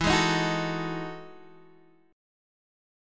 E Major 11th